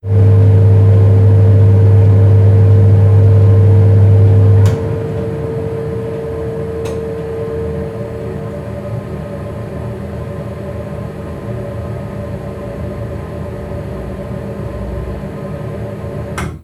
Caldera de gas: apagado
caldera
Sonidos: Industria
Sonidos: Hogar